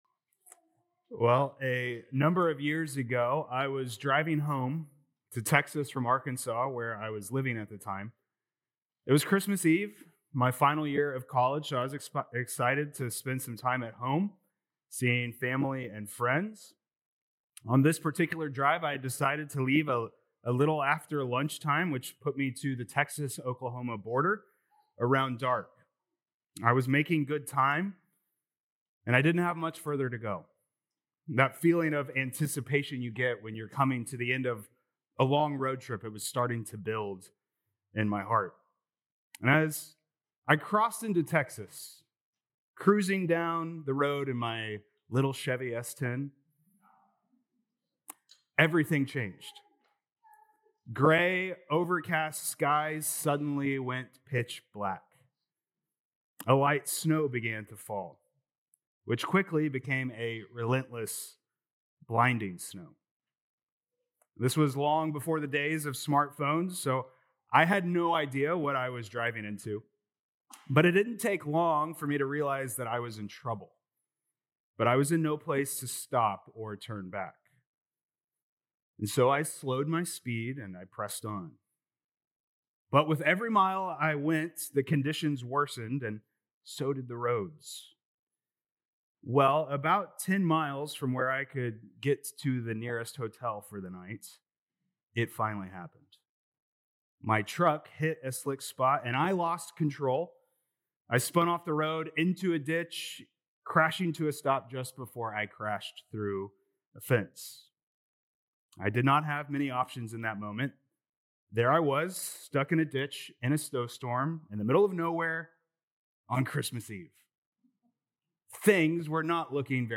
Dec 21st Sermon